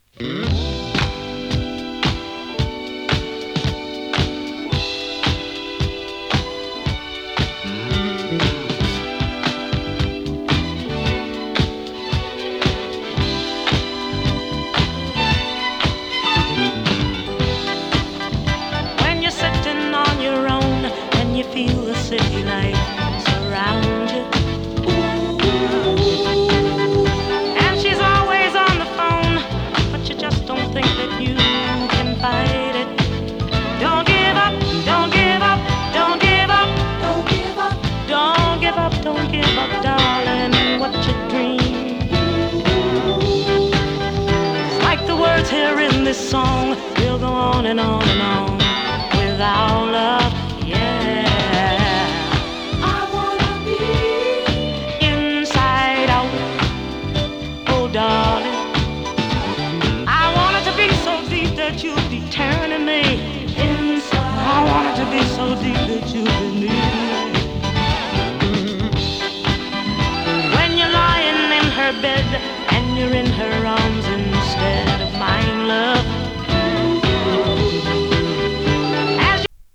切なくも力強さを感じさせるディスコ・ソウル！多くのダンスクラシックを持つ男女三人組ボーカルグループ。